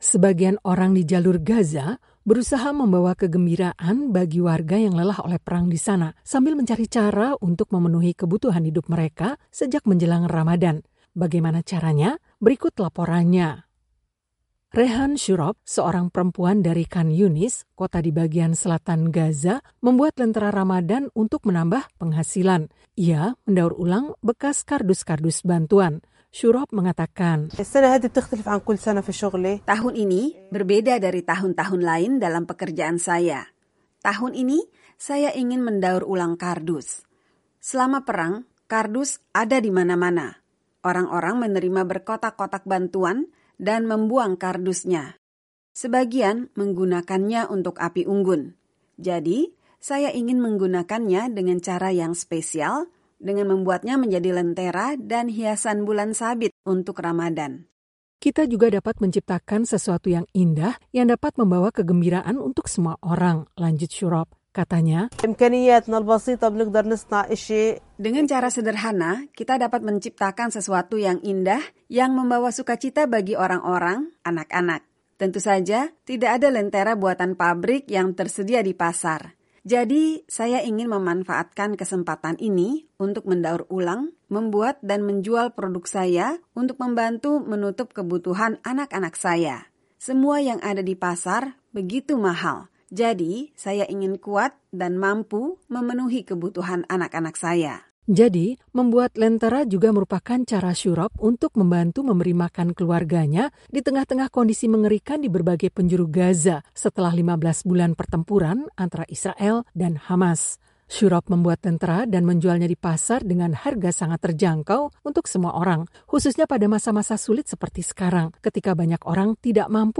Laporan Radio